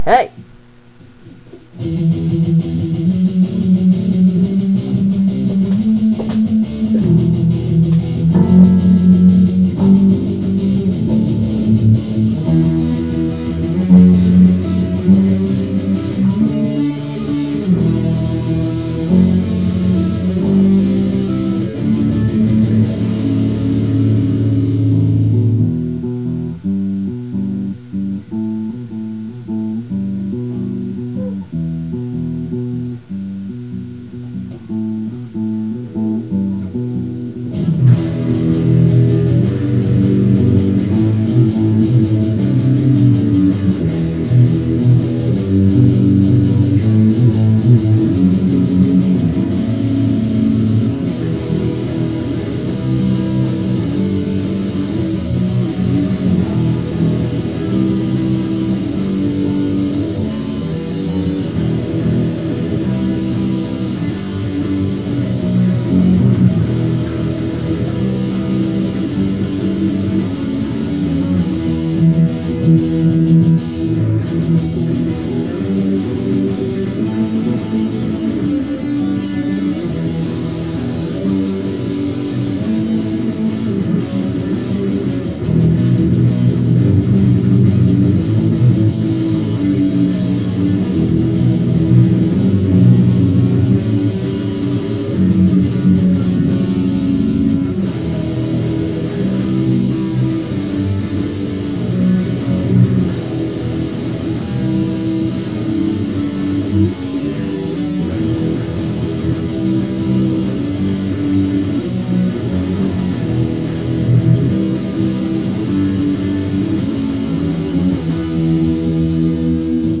reality(no sing/drums. really old)